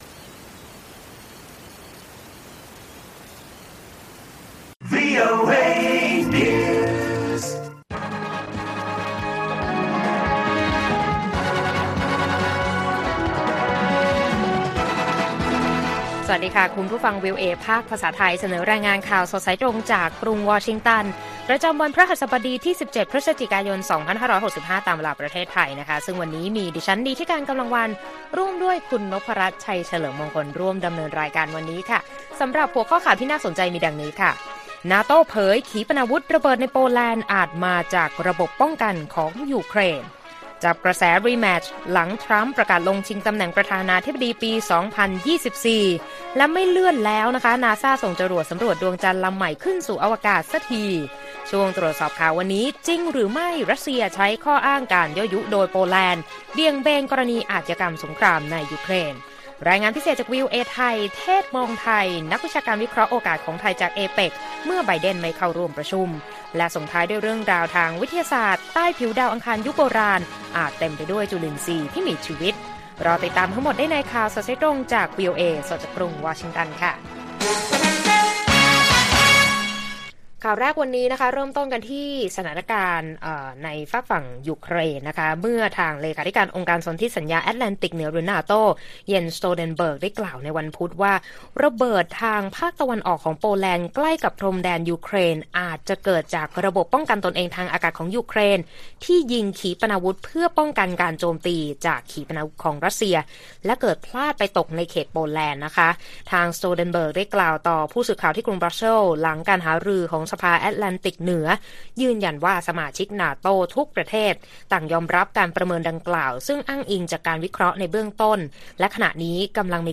ข่าวสดสายตรงจากวีโอเอ ไทย พฤหัสฯ 17 พ.ย. 65